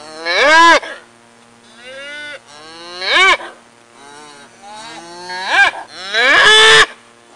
Calves Sound Effect
Download a high-quality calves sound effect.
calves.mp3